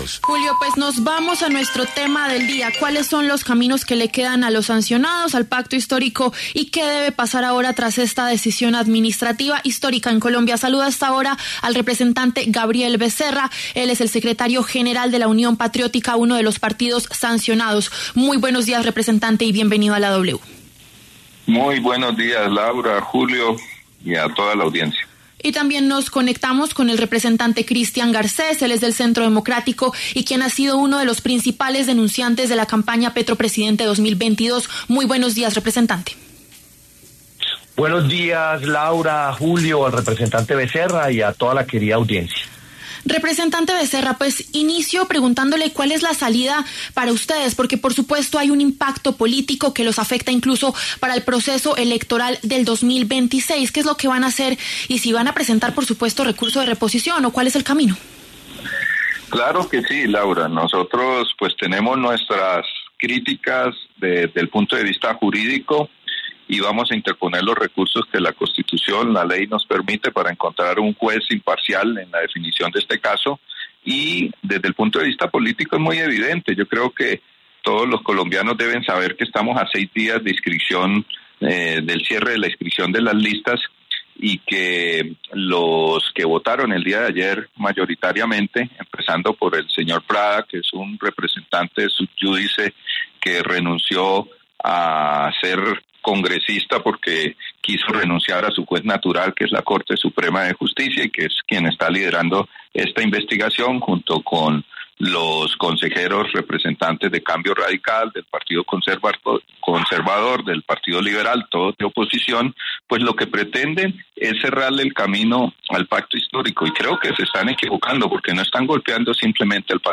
Los representantes Gabriel Becerra, del Pacto Histórico y secretario general de la Unión Patriótica, uno de los partidos sancionados, así como Christian Garcés, del Centro Democrático pasaron por los micrófonos de La W.